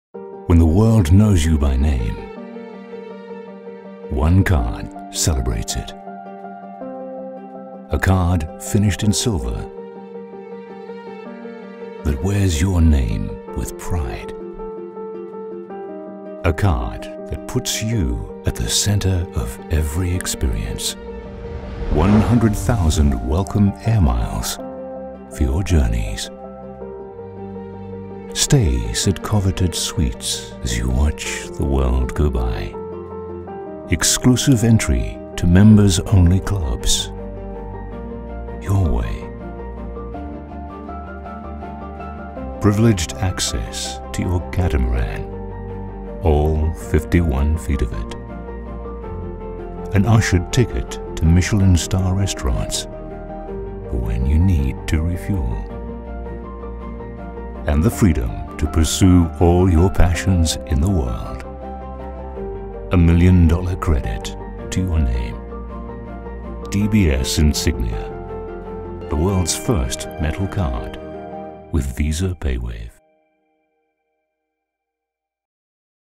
Not American but not quite British either, I have a neutral accent that has been well received in continental Europe precisely because it is neither.
English - Transatlantic
Middle Aged